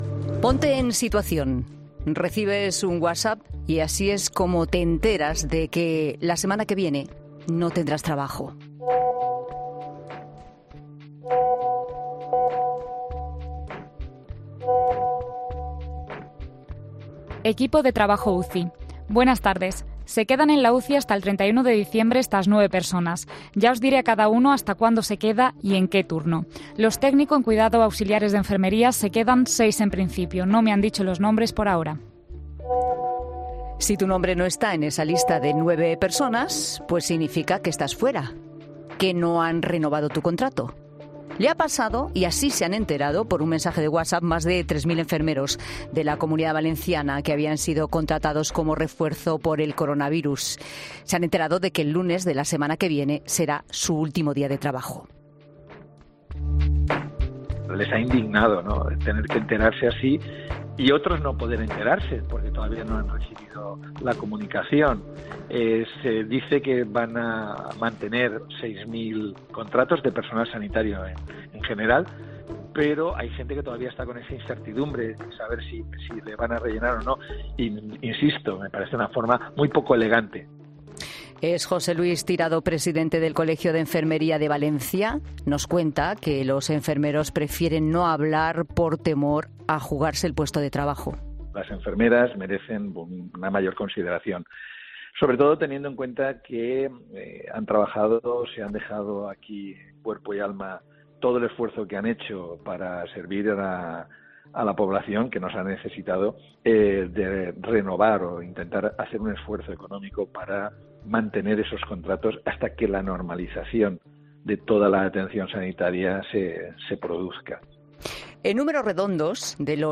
Hemos hablado en 'La Tarde' con varios miembros del sector para valorar la decisión de la Consejería de Sanidad valenciana al despedir a más de...